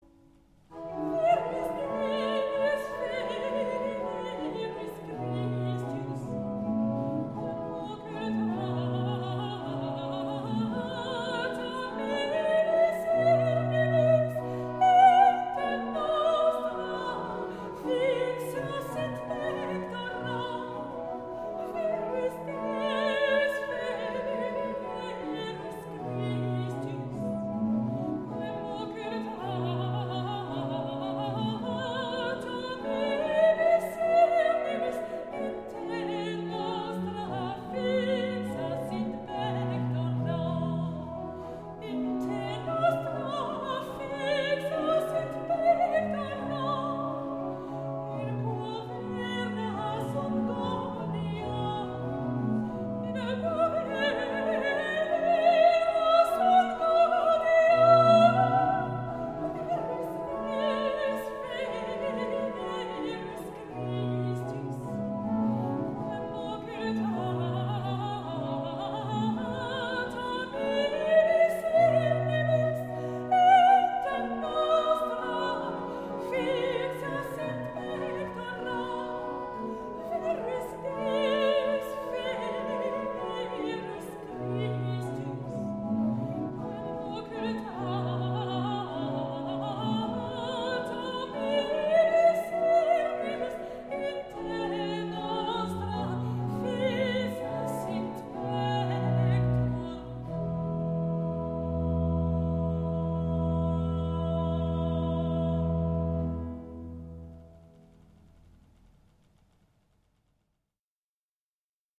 Petit motet
~1600 (Baroque)